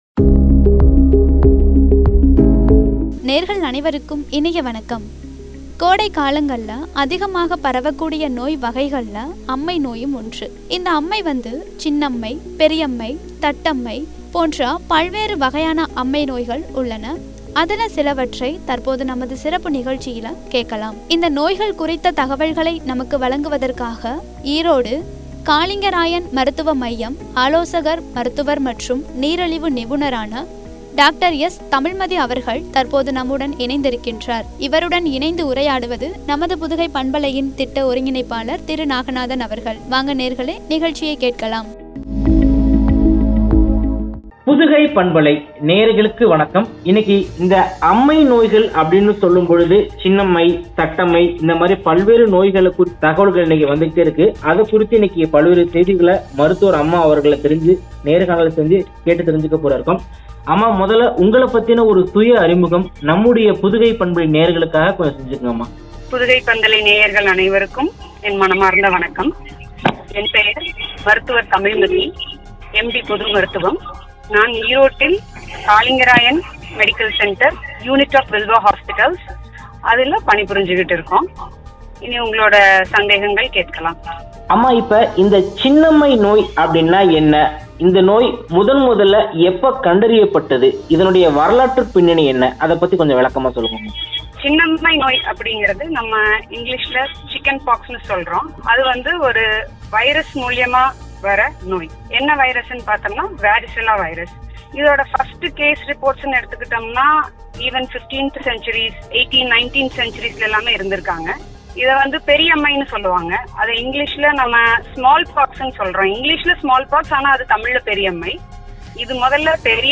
தட்டம்மை நோய் அறிகுறிகளும், தடுப்பு முறைகளும்” என்ற தலைப்பில் வழங்கிய உரையாடல்.